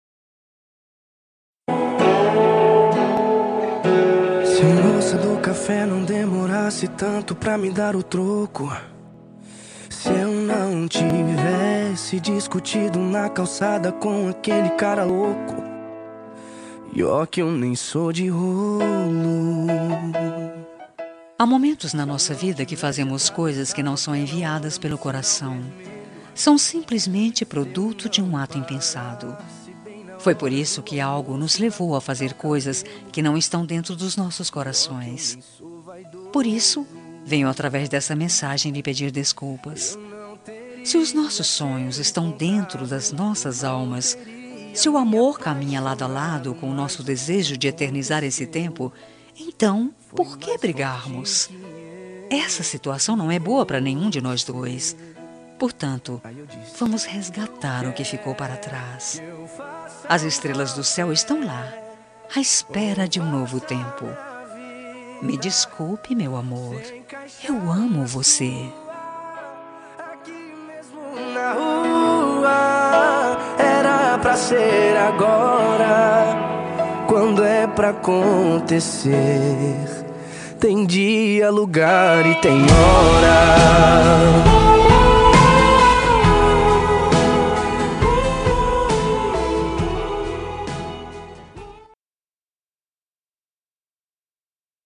Telemensagem de Desculpas – Voz Feminina – Cód: 20188